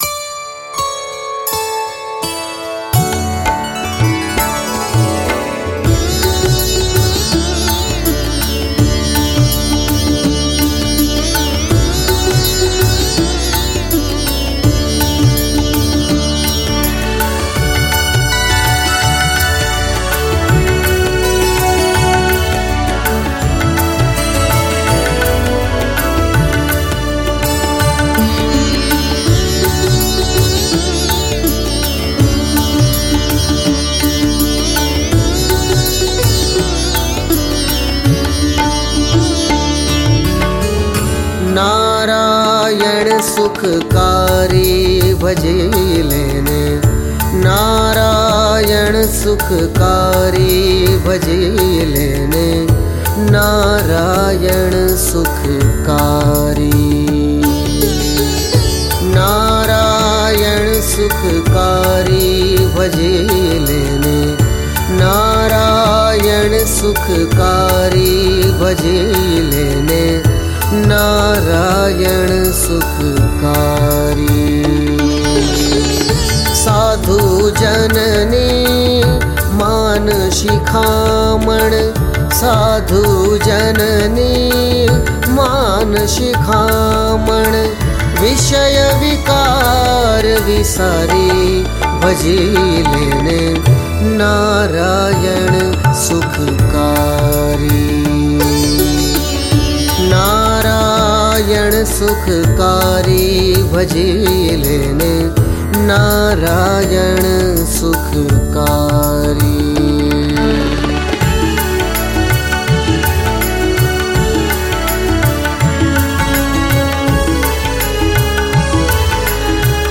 🎵 Nārāyaṇ Sukhkārī / નારાયણ સુખકારી – રાગ : ગોડી